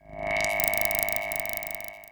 Machine10.wav